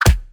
Impact_3.wav